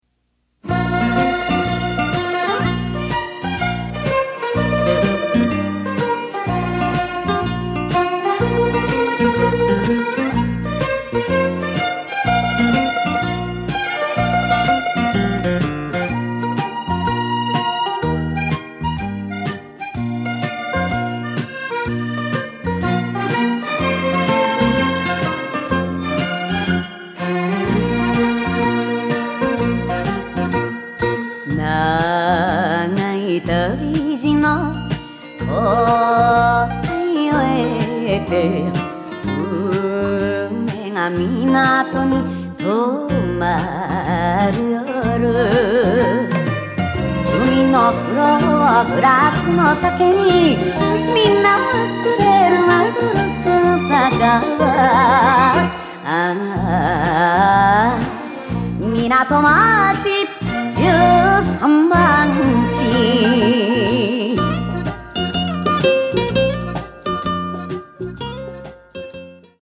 mp3はラジカセ(東芝 TY-CDX92)で作成しました。
mp3音源サンプル(A面冒頭)
歌入り カラオケ